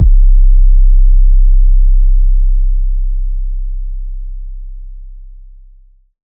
808 METRO BASS (W KICK).wav